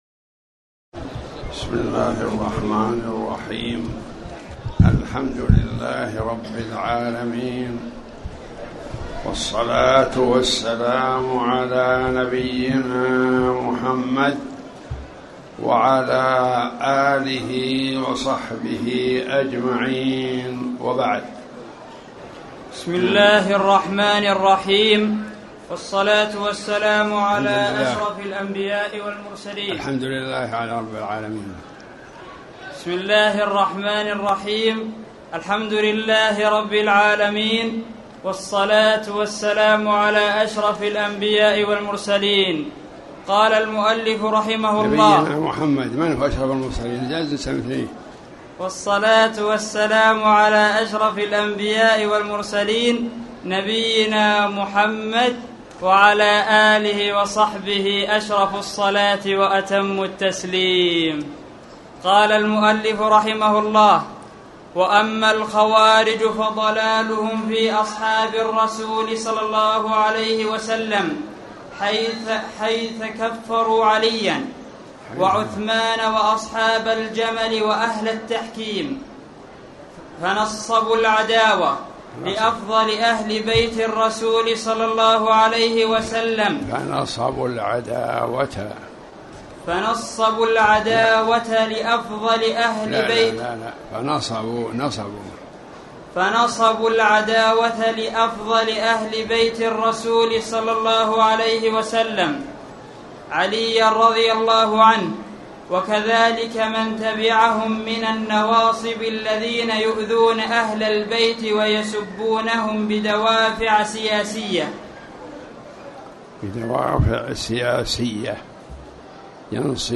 تاريخ النشر ٧ رجب ١٤٣٩ هـ المكان: المسجد الحرام الشيخ